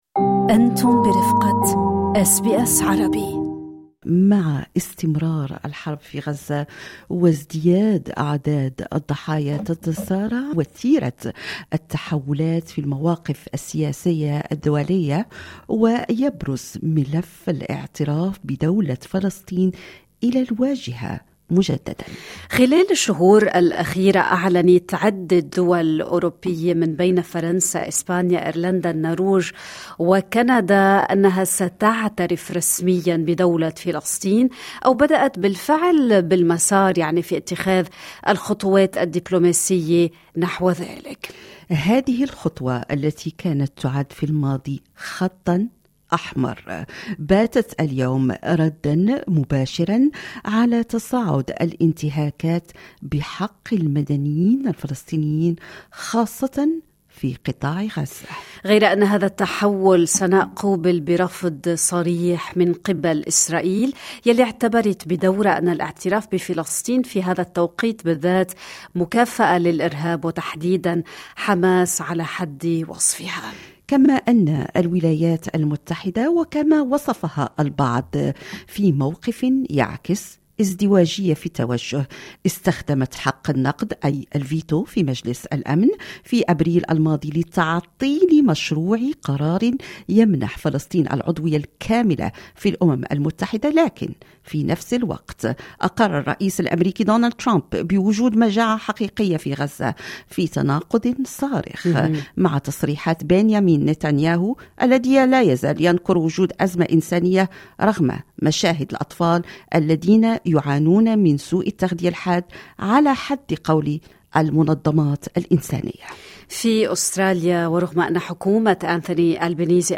هل يغيّر اعتراف كانبرا بفلسطين معادلة الصراع؟ القائمة بالأعمال بالسفارة الفلسطينية لدى أستراليا تجيب
Ms. Noura Saleh, Senior Advisor and Chargé d’Affaires at the Palestinian Embassy in Australia.